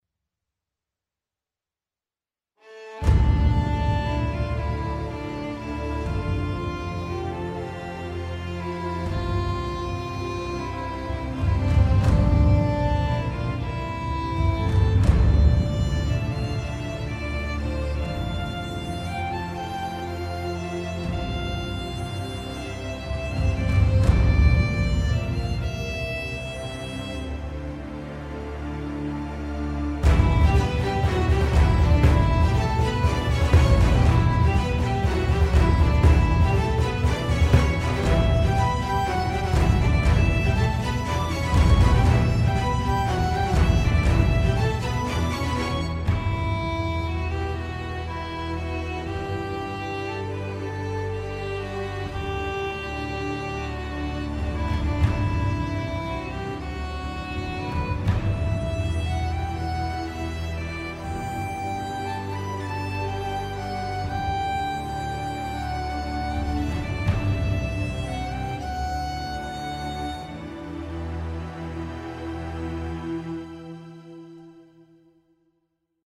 管弦乐
中提琴独奏拥有与LSS独奏弦乐捆绑包中的表兄弟相同的奇妙功能，包括三种真正的连奏类型，8RR x 3动态层断音演奏，两种真正的释放模式（硬和软），加上颤音，具有四个奇妙的可混合麦克风位置，在它们之间，可以提供种类繁多的声音托盘。
还包括断音和全音颤音，每个音符有 24 种变化，因此听起来永远不会重复 - 也可以通过按键开关切换。
这是演奏者以尖锐的渐强和快速加重音调的弓声结束音符的声音。